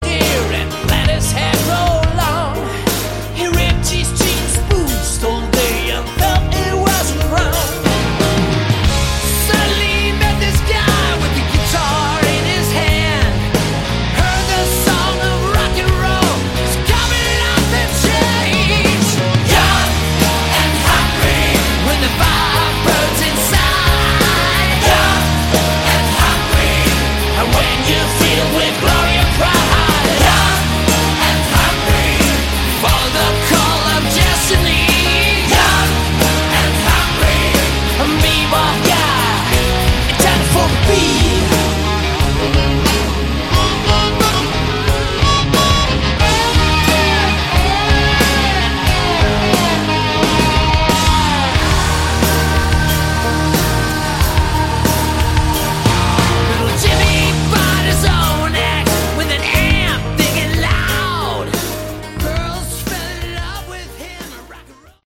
Category: Hard Rock
keyboards, piano
guitars, backing vocals
drums
vocals
bass